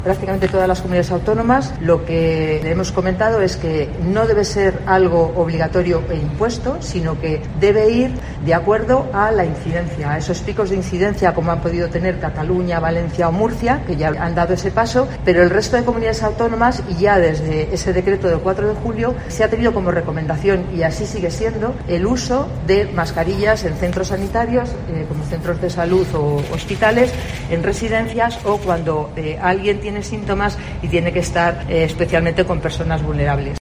Martín ha realizado estas declaraciones en un encuentro informativo, posterior a la reunión del Consejo Interterritorial del Sistema Nacional de Salud (CISNS), en el que también ha participado la directora general de Salud Pública, Consumo y Cuidados, Eva Martínez.